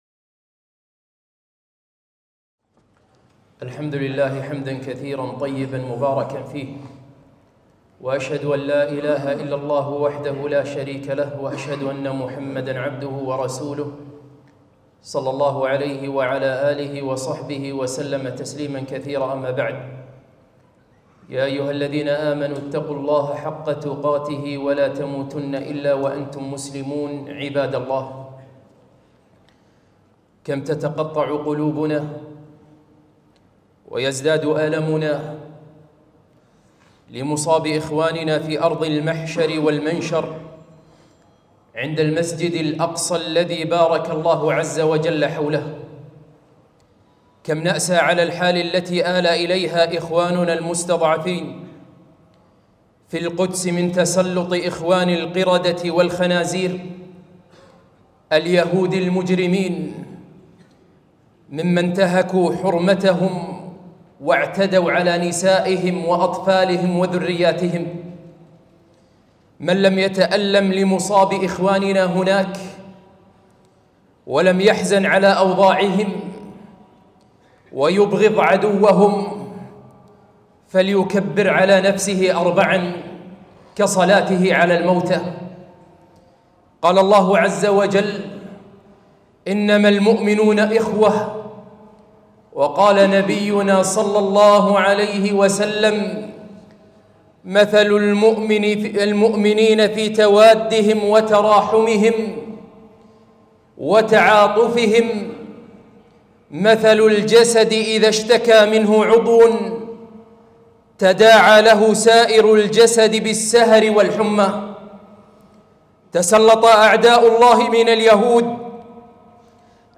خطبة - تقطع القلوب على بيت المقدس وأهله